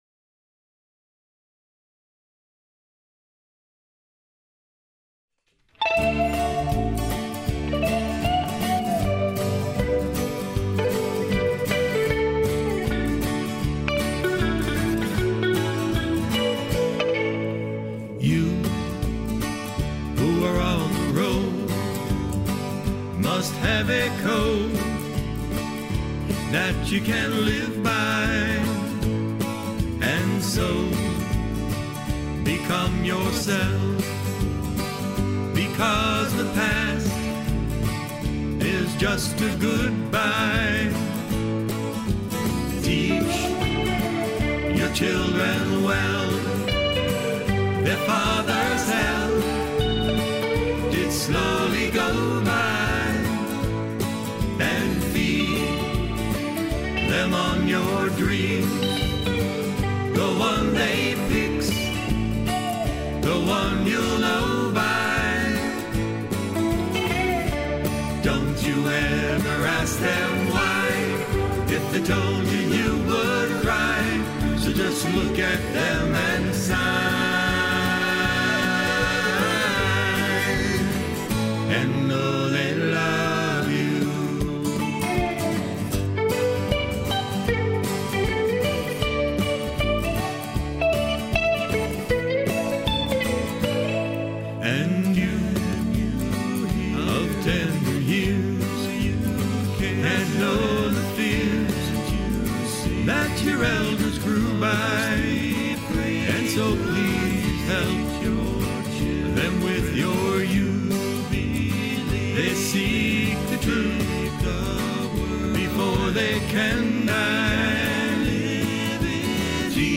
ACOUSTIC GUITAR = 1964 Guild DST45
DRUMS = Alesis SR18